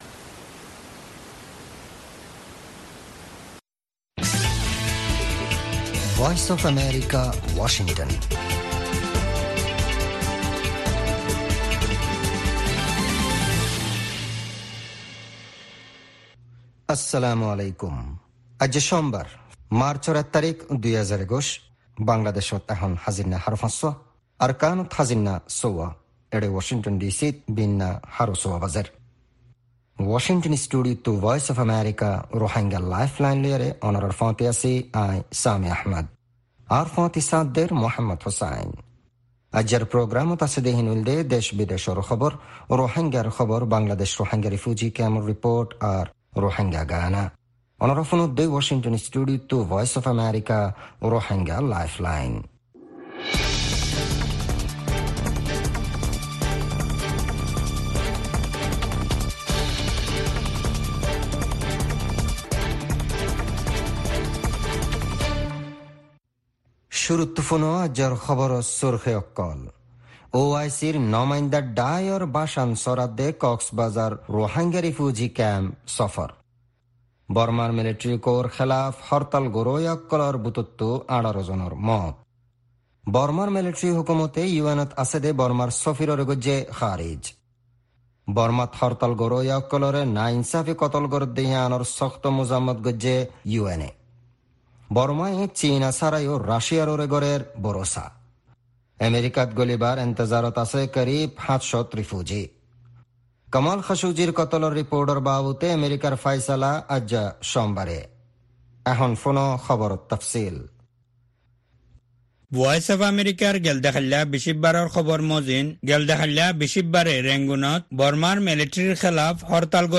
Rohingya “Lifeline” radio
News Headlines